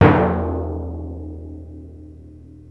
TIMP 3.WAV